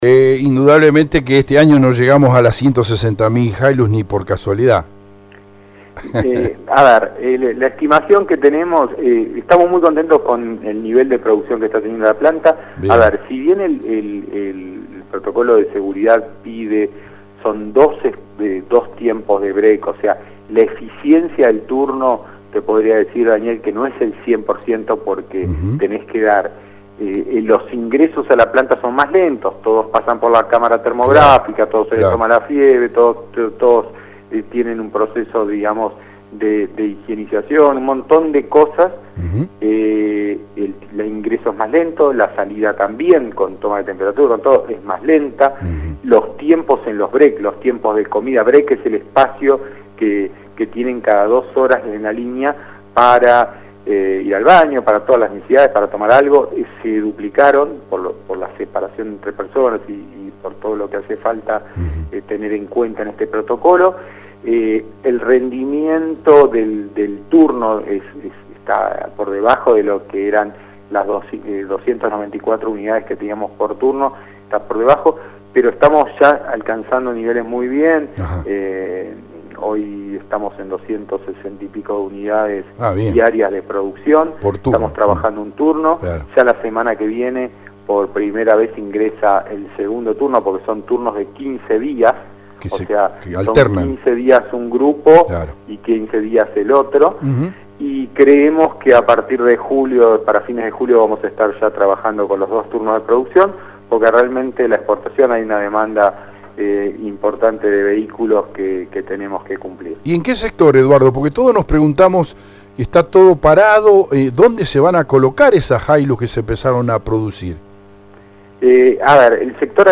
ENTREVISTA DE LA MAÑANA DE HOY